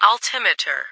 - New ATIS Sound files created with Google TTS en-US-Studio-O
Altimeter.ogg